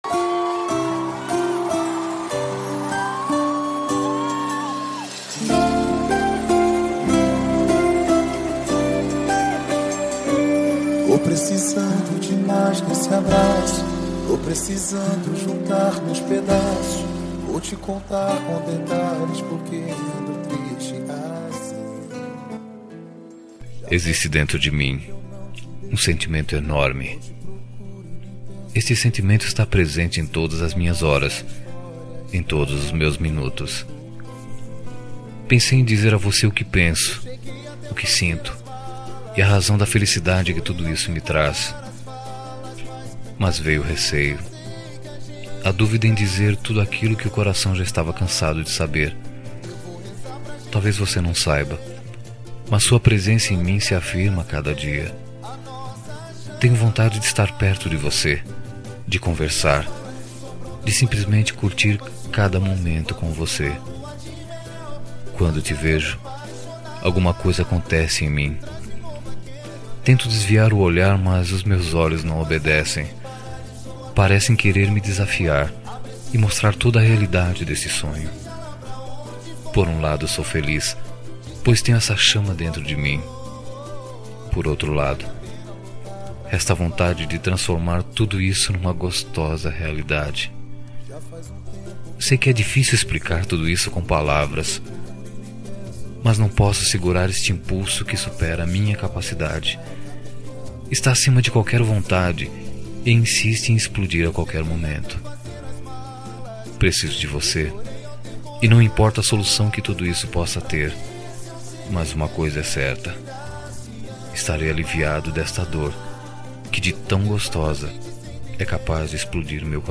Telemensagem de Reconciliação – Voz Masculina – Cód: 035321